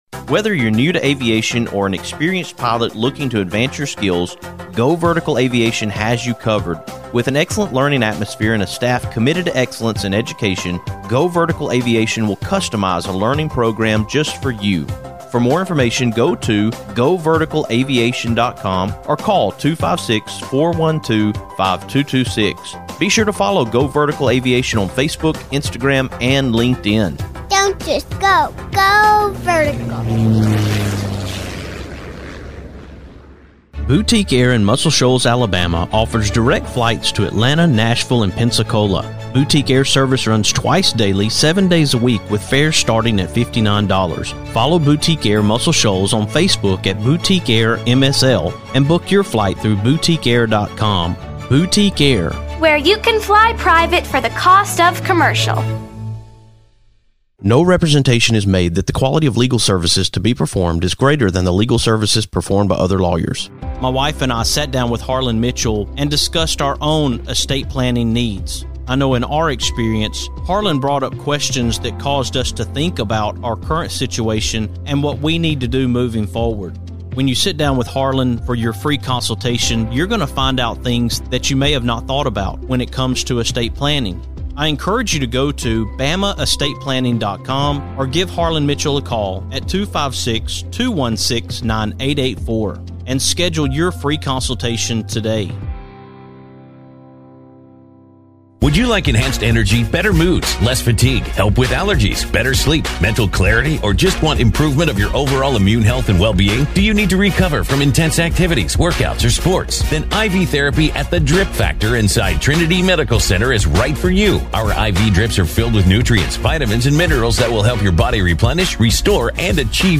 On this afternoon’s show, we are heading to the Alabama Veterans Museum and Archives in Athens, Alabama, to talk with members of the John Wade Keyes DAR (Daughters of the American Revolution) Chapter!